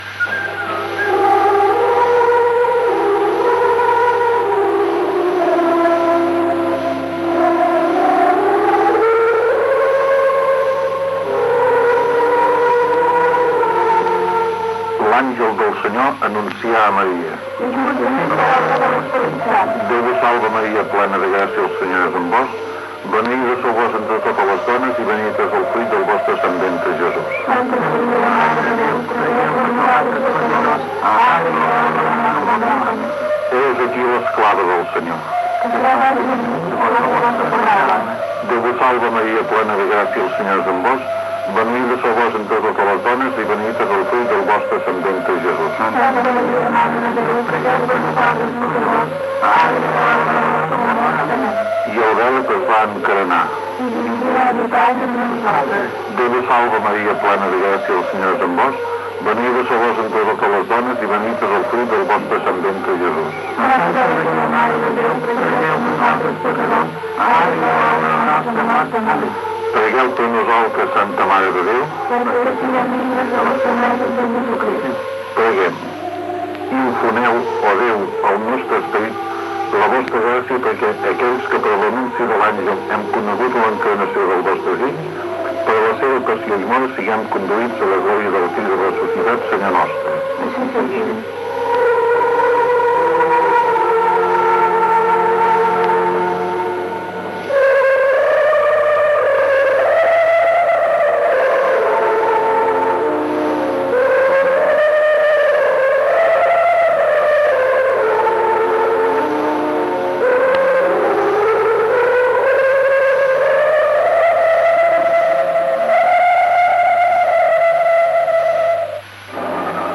L'Àngelus: res de l'Ave Maria i precs. Publicitat
Religió